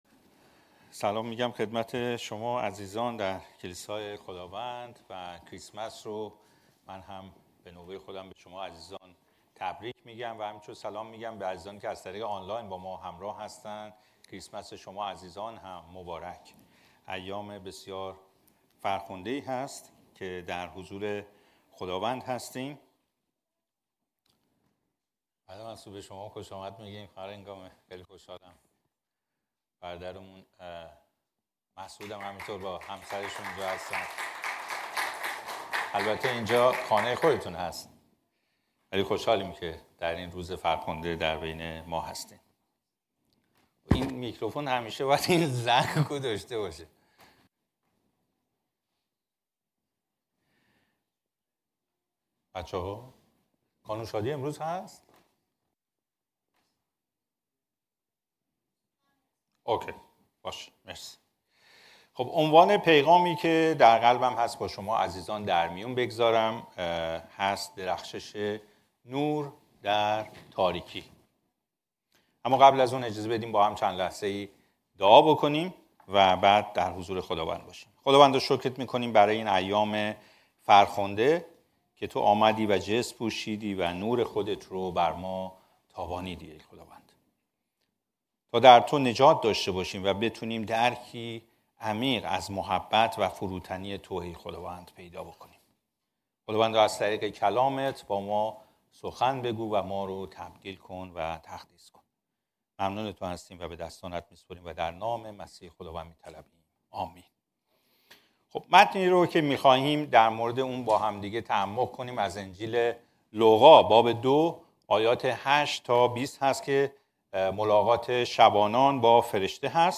موعظه‌ها